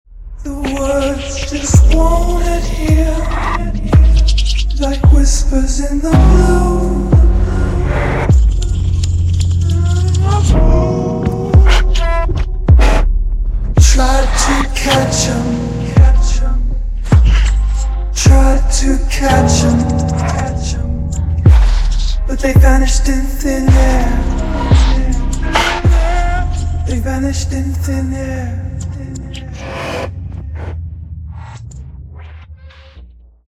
Short version of the song, full version after purchase.
An incredible Soul Searching song, creative and inspiring.